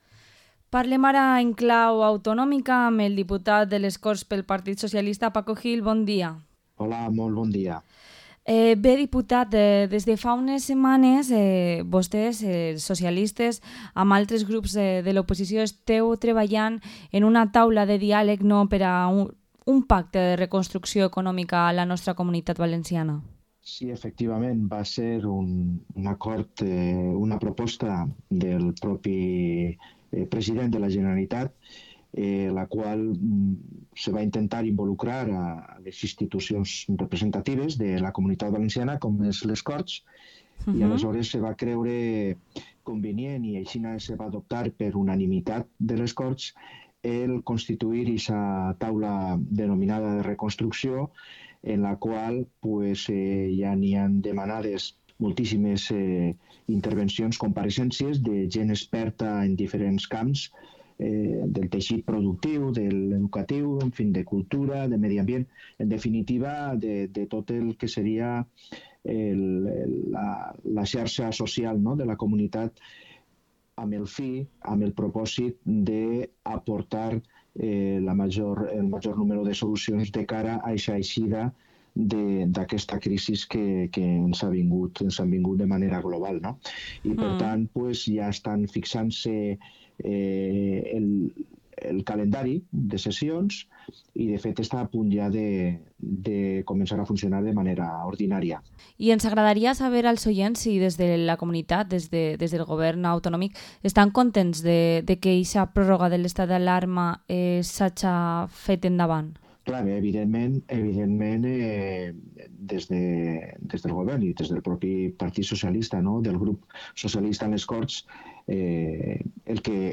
Entrevista al diputado autonómico del PSPV-PSOE, Paco Gil